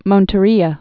(mōntə-rēə)